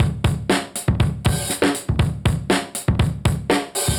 Index of /musicradar/dusty-funk-samples/Beats/120bpm/Alt Sound